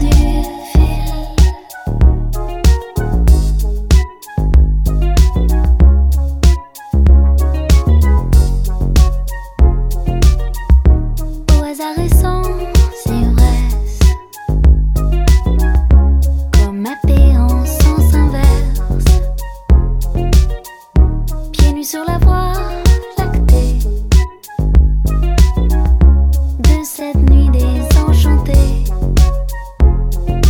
Chansons francophones